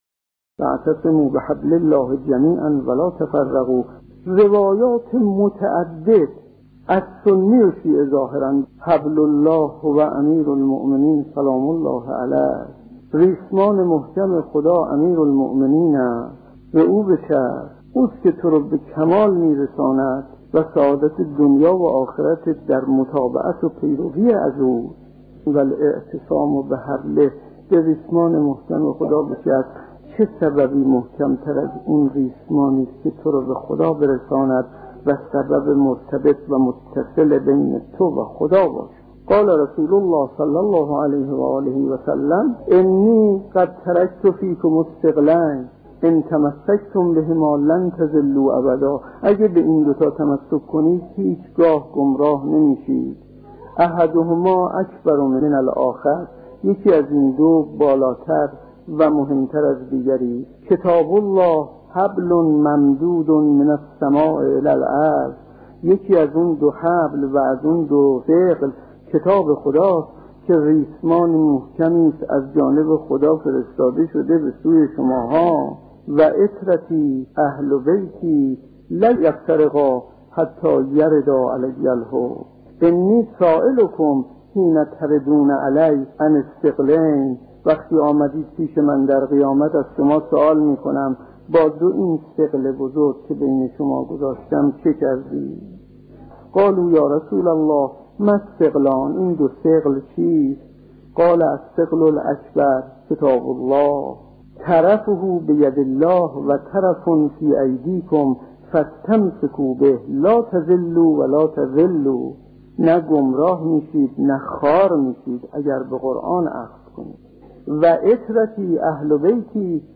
، فضائل امیرالمومنین